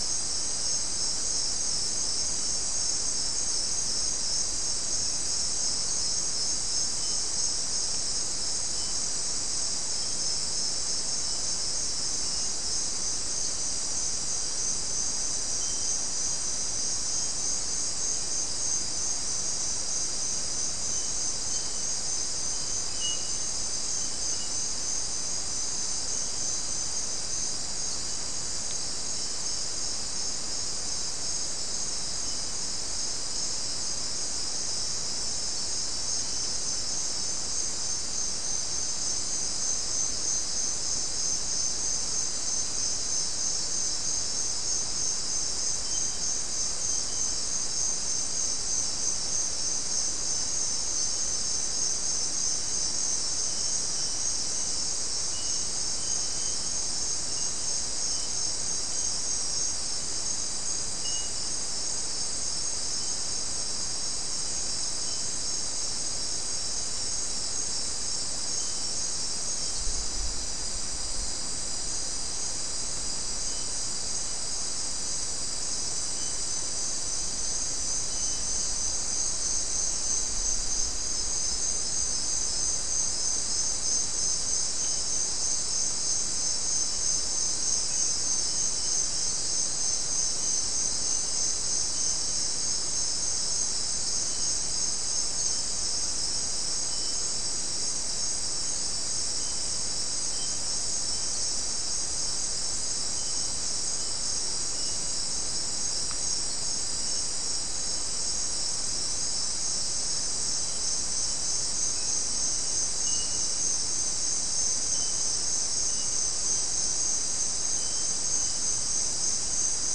Soundscape Recording
South America: Guyana: Turtle Mountain: 2
Recorder: SM3